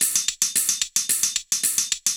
Index of /musicradar/ultimate-hihat-samples/110bpm
UHH_ElectroHatB_110-01.wav